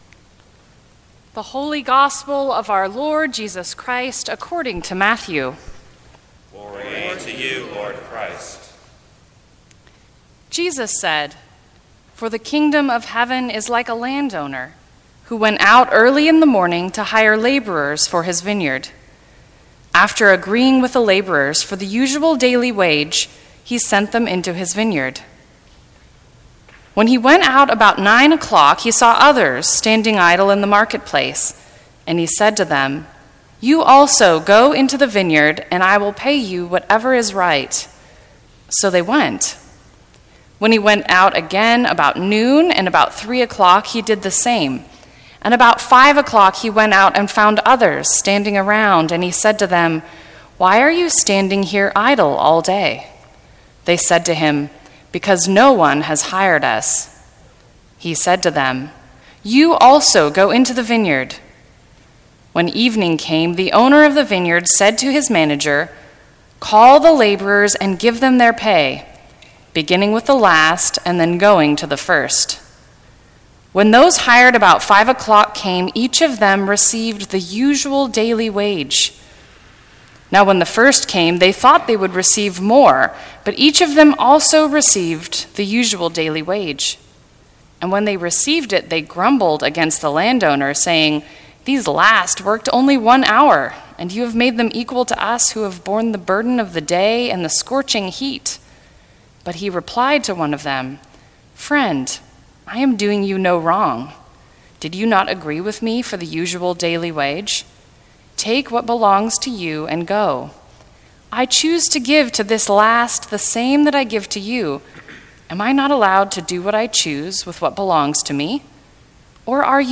Sixteenth Sunday after Pentecost, 8 AM
Sermons from St. Cross Episcopal Church Our Common Life Sep 29 2017 | 00:15:40 1x 00:00 / 00:15:40 Subscribe Share Apple Podcasts Spotify Overcast RSS Feed Share Link Embed